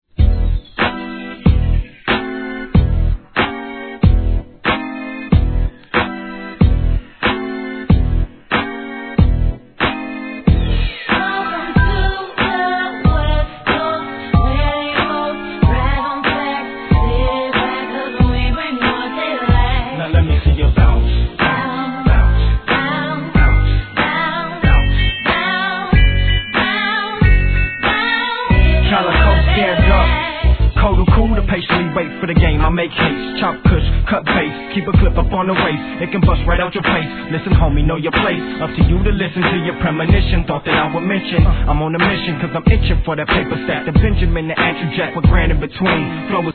HIP HOP/R&B
不穏なシンセ・ラインがBADな2004年、MID BOUNCE!